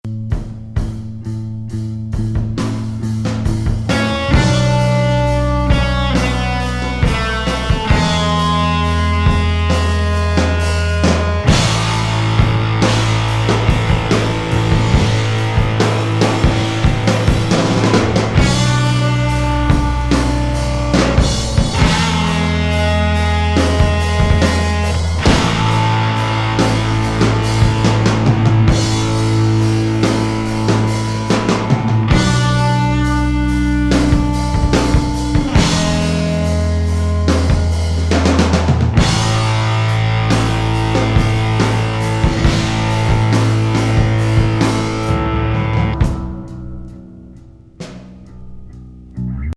ワシントン発インスト・ポストロック・バンドの95年作1ST!オルタナ経由のピコピコ